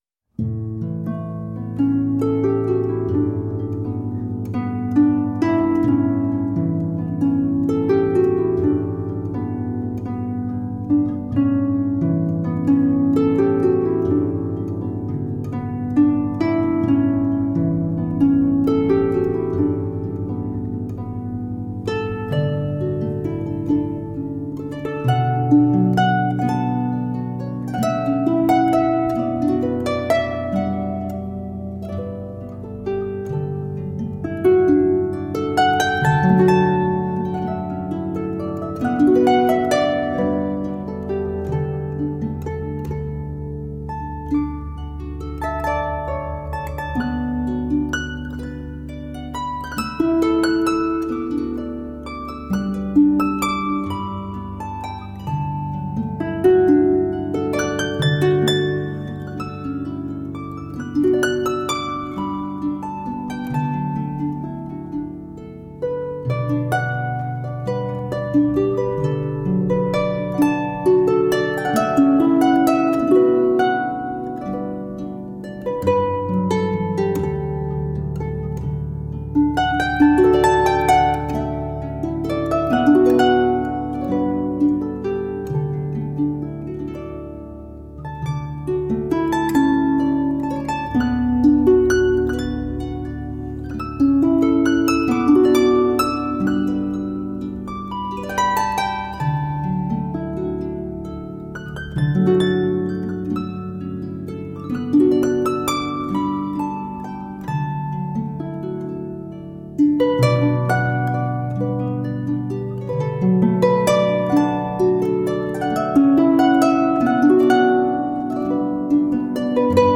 Timeless and enchanting folk music for the soul.
Solo Celtic Harp. Perfect for rest and relaxation.
Just soft soothing solo harp music.
Tagged as: World, Folk, Celtic, Harp, Massage